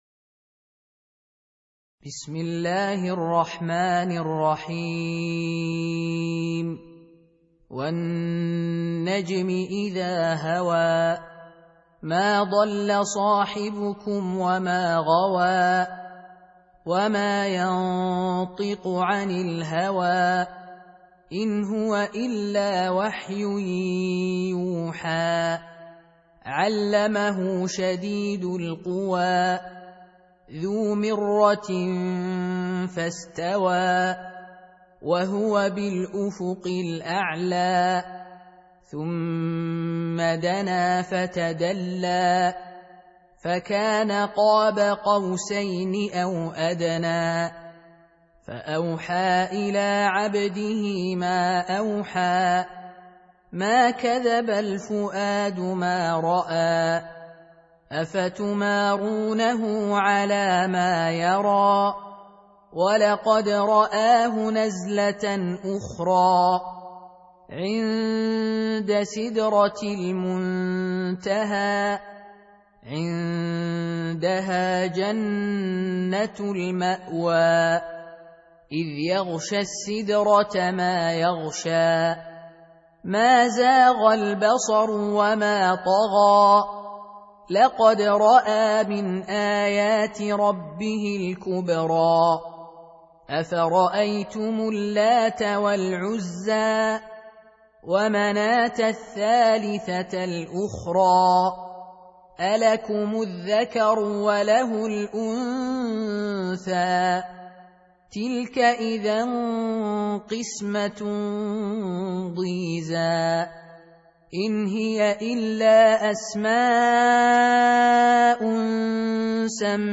Surah Repeating تكرار السورة Download Surah حمّل السورة Reciting Murattalah Audio for 53. Surah An-Najm سورة النجم N.B *Surah Includes Al-Basmalah Reciters Sequents تتابع التلاوات Reciters Repeats تكرار التلاوات